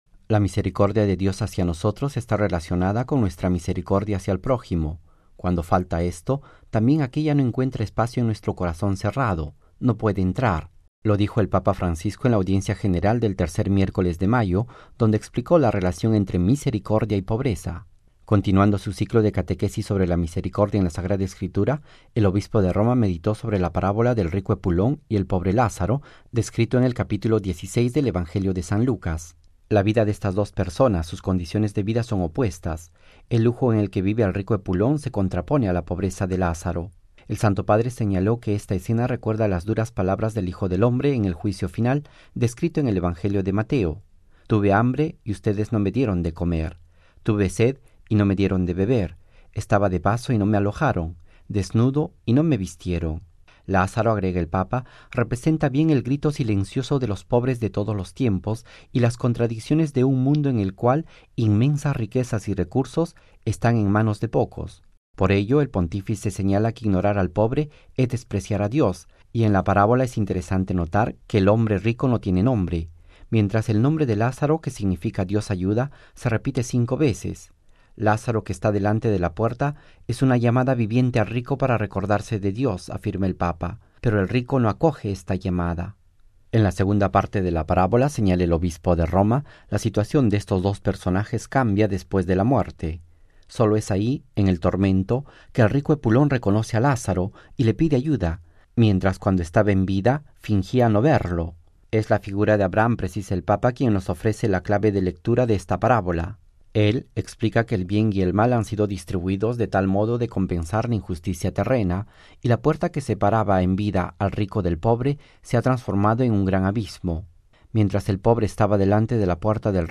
(RV).- “La misericordia de Dios hacia nosotros está relacionada con nuestra misericordia hacia el prójimo; cuando falta esto, también aquella no encuentra espacio en nuestro corazón cerrado, no puede entrar”, lo dijo el Papa Francisco en la Audiencia General del tercer miércoles de mayo, donde explicó la relación entre “misericordia y pobreza”.
Texto y audio completo de la catequesis del Papa Francisco: